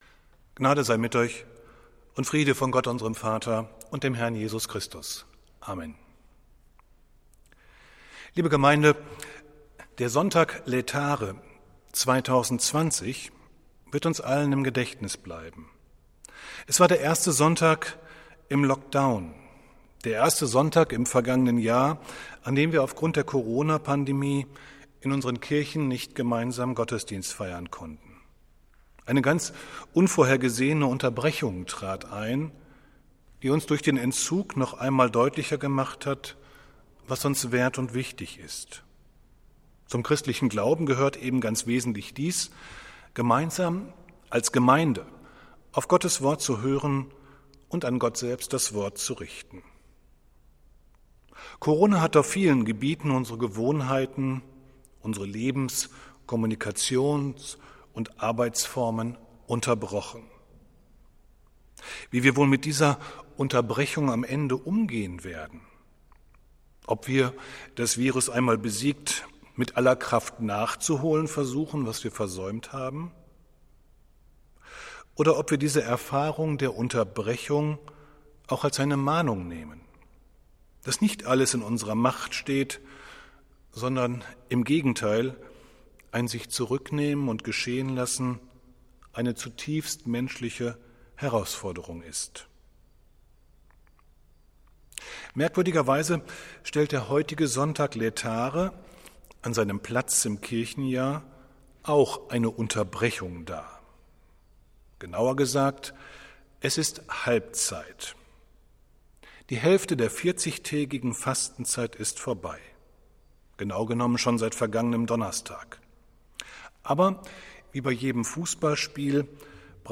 Predigt des Gottesdienstes aus der Zionskirche vom Sonntag, 14.03.2021
Wir haben uns daher in Absprache mit der Zionskirche entschlossen, die Predigten zum Nachhören anzubieten.